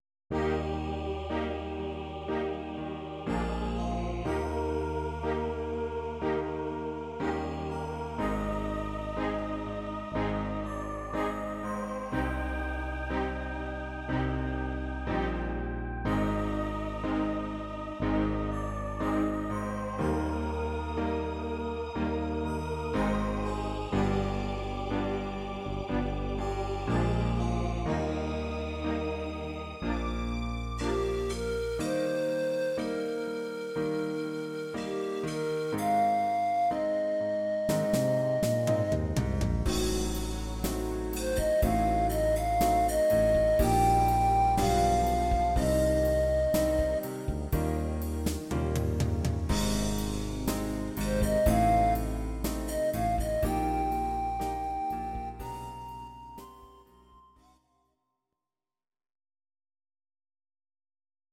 Audio Recordings based on Midi-files
Our Suggestions, Pop, Ital/French/Span, 1970s